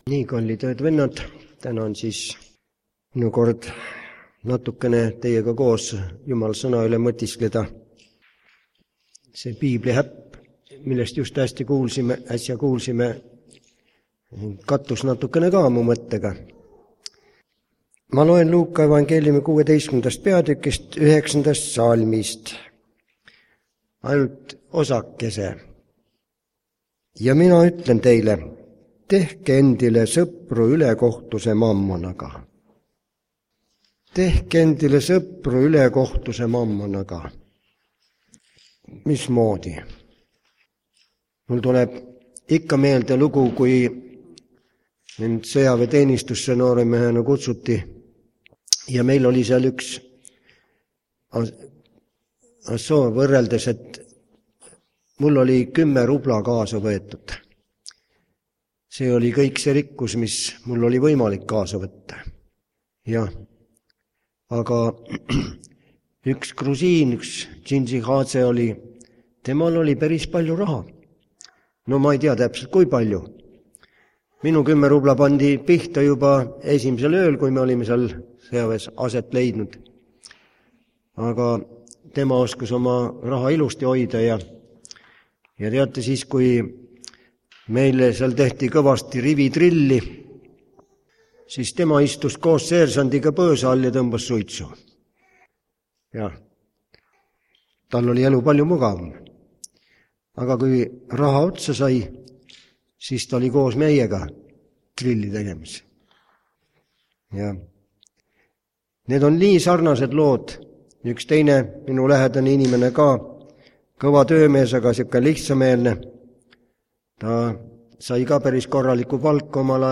Lauluhekeks vanem laul aastast 1989